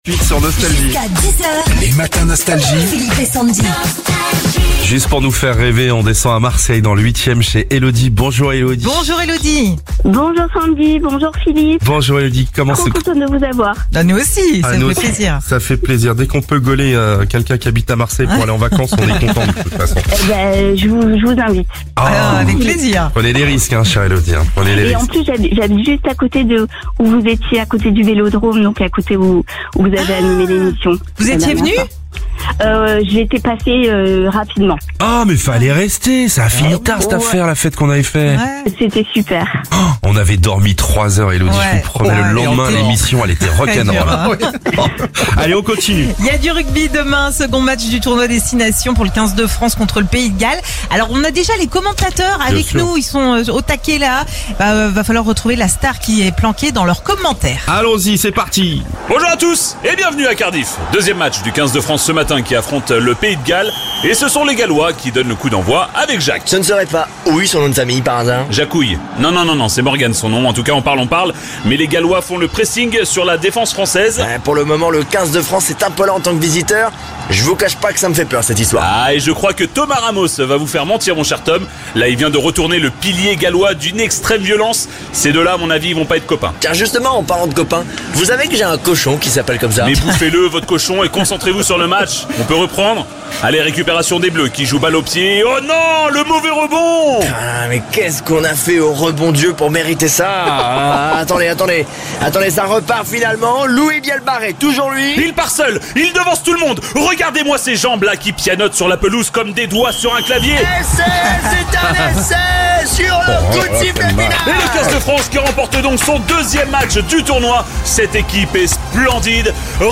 Les commentateurs du match sont déjà avec nous…À vous de retrouver la célébrité qui s’est planquée dans leurs commentaires pour gagner vos nouveaux écouteurs.